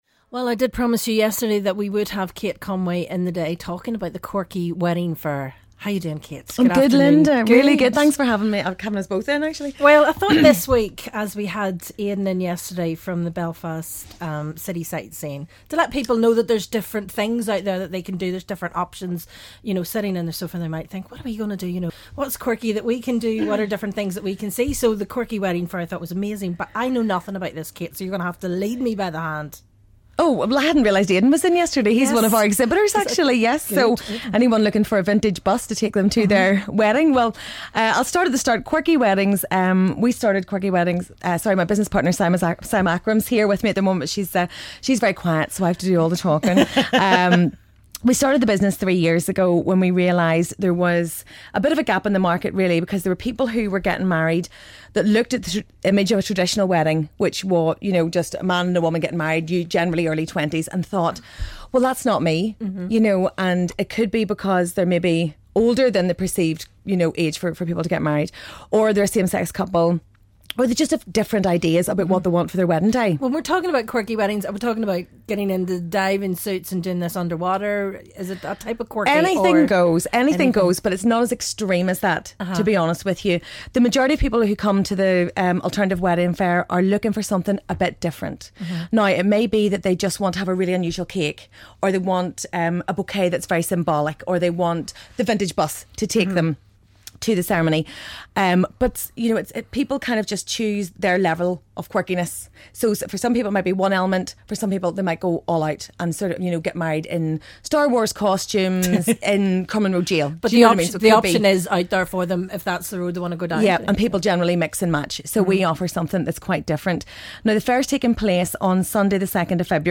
Interview - Quirky Wedding Fair